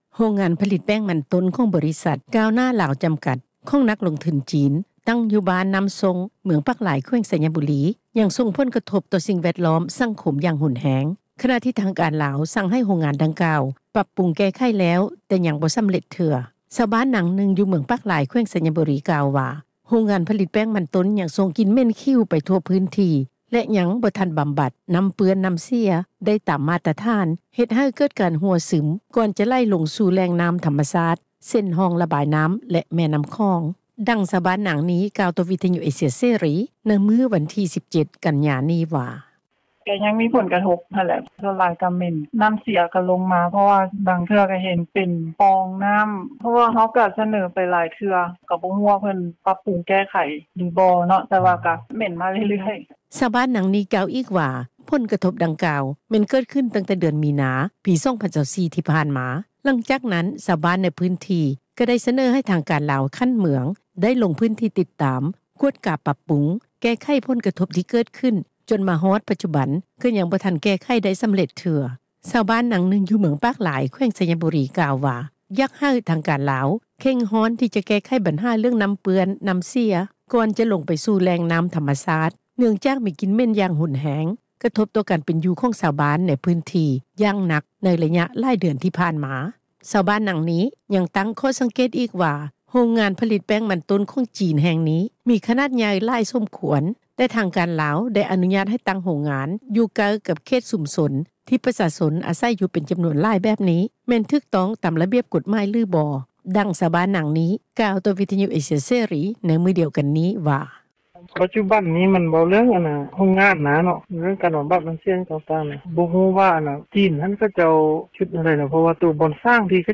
ດັ່ງ ຊາວບ້ານນາງນີ້ ກ່າວຕໍ່ວິທຍຸ ເອເຊັຽເສຣີ ໃນມື້ວັນທີ 17 ກັນຍາ ນີ້ວ່າ:
ດັ່ງ ເຈົ້າໜ້າທີ່ ທ່ານນີ້ ກ່າວຕໍ່ວິທຍຸເອຊັຽ ເສຣີ ໃນມື້ດຽວກັນນີ້ວ່າ: